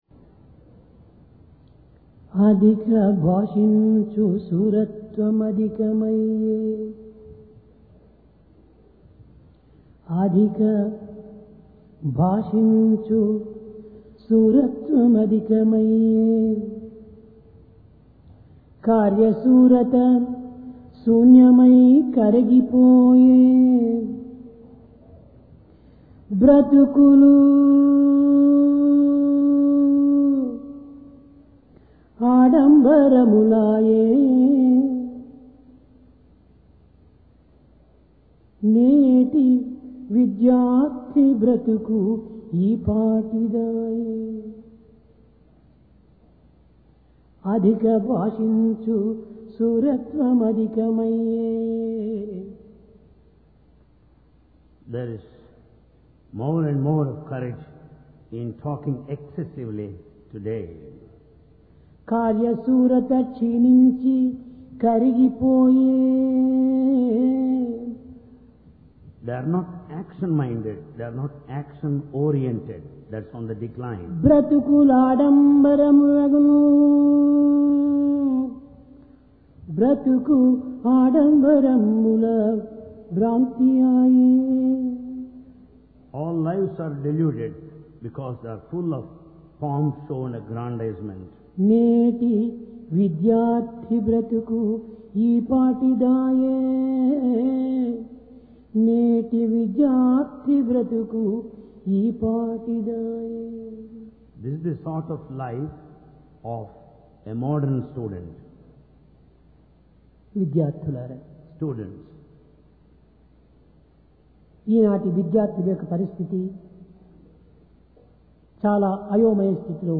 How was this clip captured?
Sai Darshan Home Date: 27 Jun 1996 Occasion: Divine Discourse Place: Prashanti Nilayam Talk Less And Work More Today, man's eloquence in speech has increased greatly, but his dexterity in action cannot be seen.